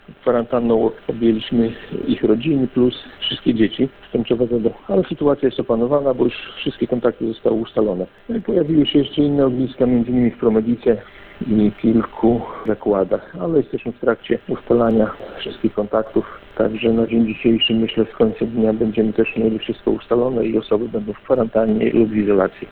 Jak informuje Mariusz Oszmian, powiatowy inspektor sanitarno-epidemiologiczny w Ełku, ustalone zostały już wszystkie kontakty.